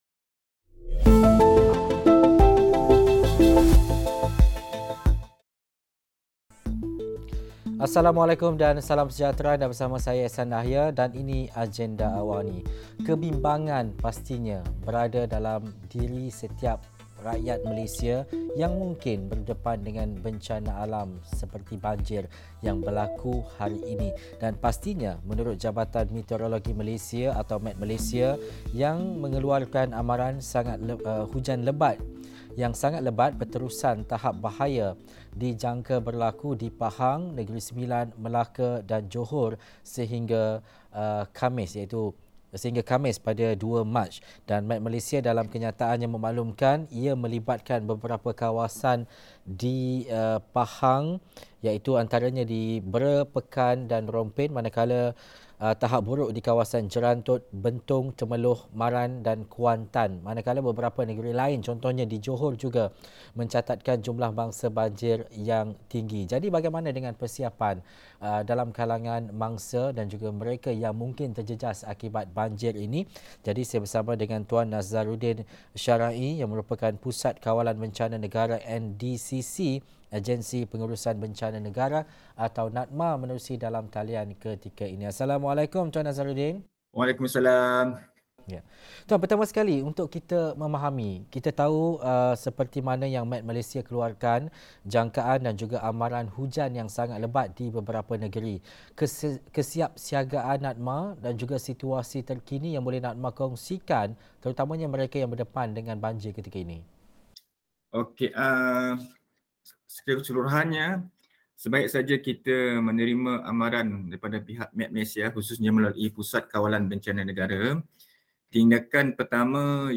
Banjir semakin memburuk, apa persiapan yang perlu dilakukan untuk pastikan pengurusan bencana dan penyelarasan bantuan kepada mangsa banjir di tahap terbaik? Diskusi 8.30 malam